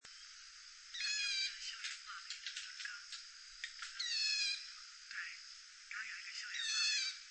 8-3紅嘴黑鵯扇平2.mp3
紅嘴黑鵯(台灣亞種) Hypsipetes leucocephalus nigerrimus
錄音地點 高雄市 六龜區 扇平
錄音環境 森林
行為描述 鳴叫
收音: 廠牌 Sennheiser 型號 ME 67